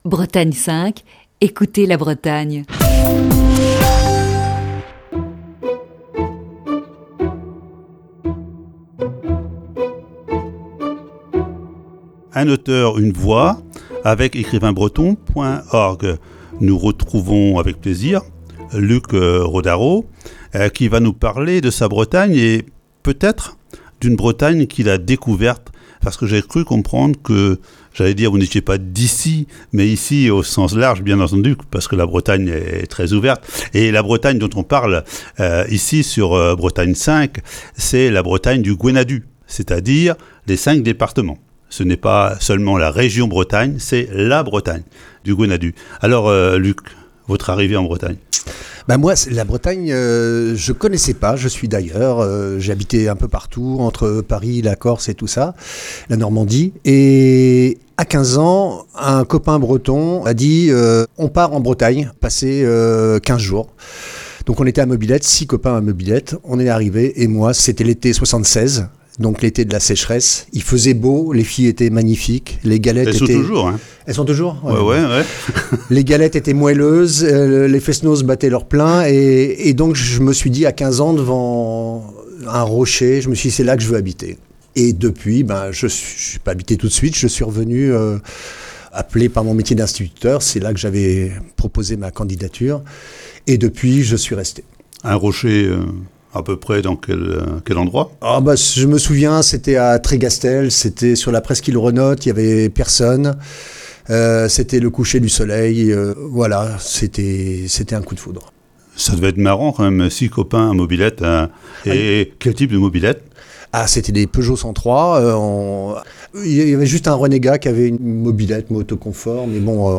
Ce matin, deuxième partie de cette série d'entretiens.